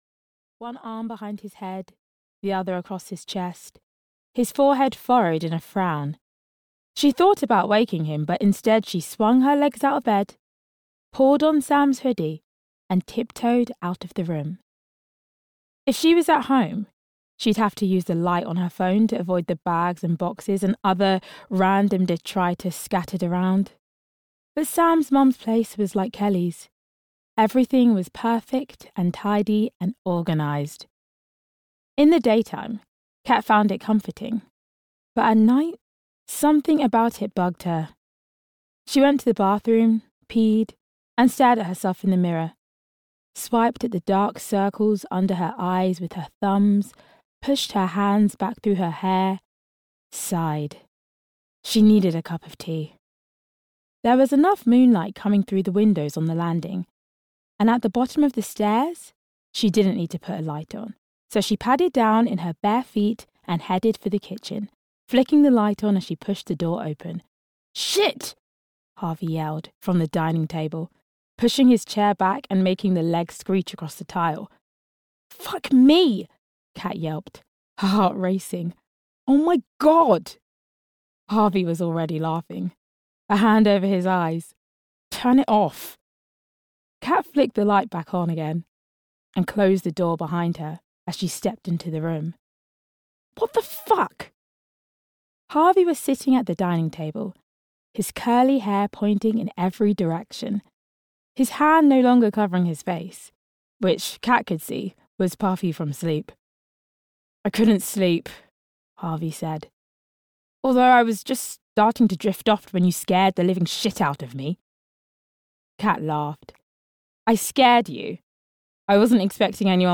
The One Who's Not the One (EN) audiokniha
Ukázka z knihy